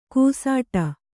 ♪ kūsāṭa